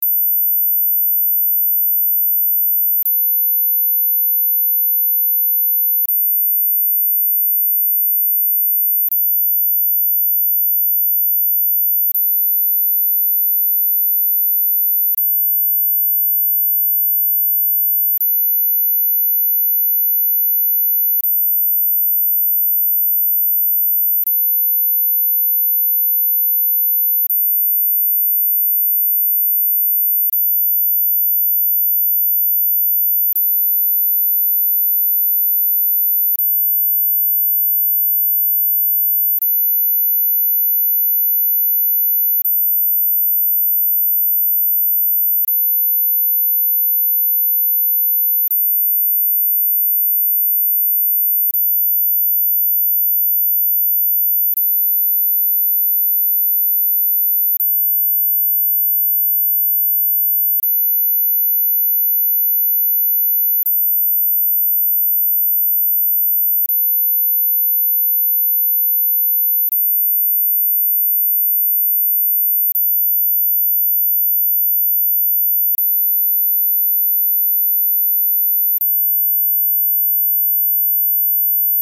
X14 kHz Frequency (45 & Younger can hear)